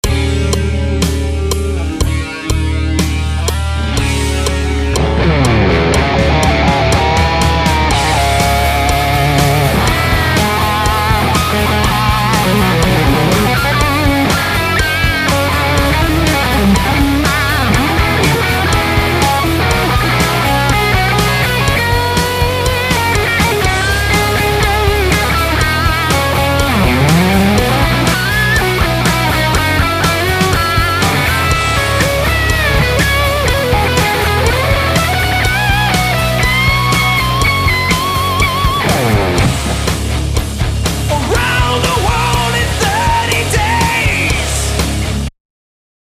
Steavens Poundcake via a Heritage 2x12, a Sennheiser 609 on the Vintage30 and an SM57 on the G12H30, I panned the two tracks 24% L/R. Guitar is a McCarty with a BareKnuckle MiracleMan in the bridge.